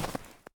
update snow step sounds
snow_1.ogg